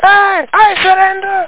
Amiga 8-bit Sampled Voice
Surrender.mp3